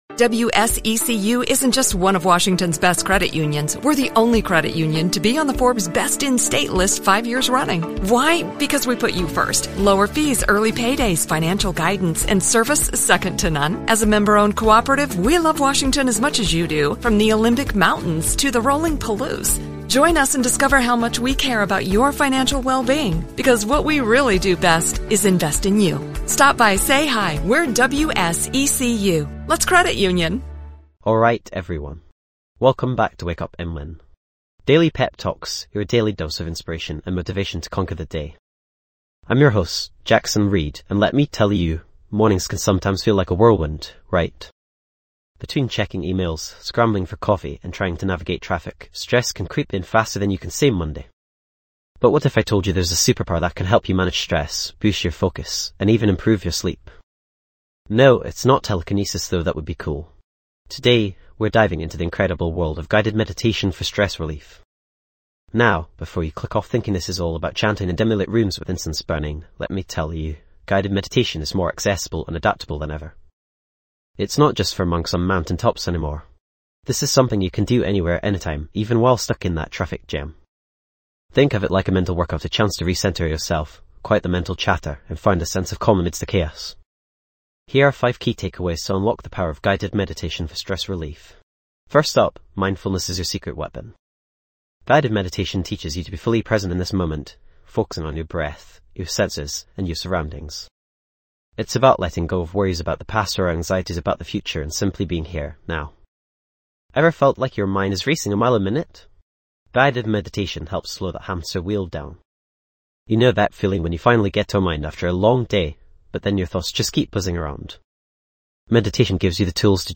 Experience the soothing voice that will help you find inner peace, calm your mind, and rejuvenate your spirit on Wake Up & Win:. Daily Pep Talks.
Episode Tags:. Guided Meditation, Stress Relief, Mindfulness, Relaxation, Calm Mind, Peaceful Journey.
This podcast is created with the help of advanced AI to deliver thoughtful affirmations and positive messages just for you.